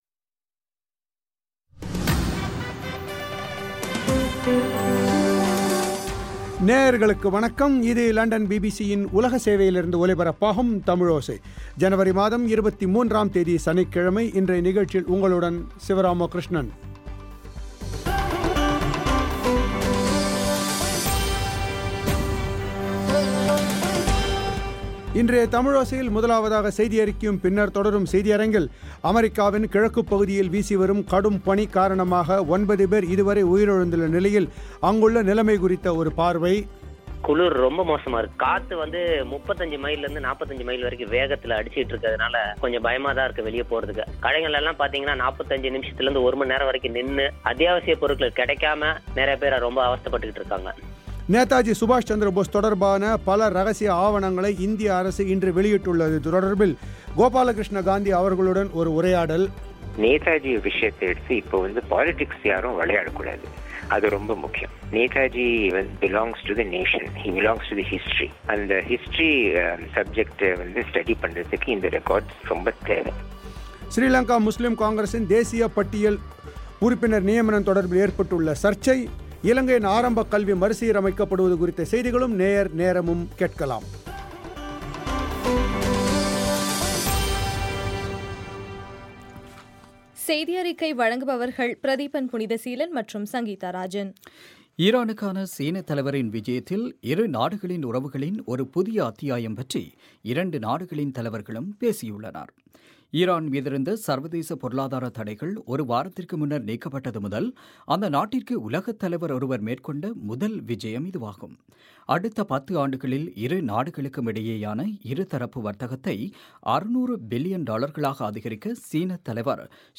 அமெரிக்காவின் கிழக்கு பகுதியில் வீசிவரும் கடும் பனி காரணமாக 9 பேர் இதுவரை உயிரிழந்துள்ளது, அங்கு நிலவும் சூழல் குறித்த பார்வையும் நேதாஜி சுபாஷ் சந்திர போஸ் தொடர்பான பல இரகசிய ஆவணங்களை இந்திய அரசு இன்று வெளியிட்டுள்ளது தொடர்பில், கோபாலகிருஷ்ண காந்தி அவர்களுடன் ஒரு உரையாடல் ஸ்ரீங்கா முஸ்லிம் காங்கிரஸின் தேசியப் பட்டியல் உறுப்பினர் நியமனம் தொடர்பில் ஏற்பட்டுள்ள சர்ச்சை இலங்கையின் ஆரம்பக கல்வி முறையை மறுசீரமைக்க அரசு முடிவெடுத்துள்ளது நேயர் நேரம் ஆகியவை கேட்கலாம்.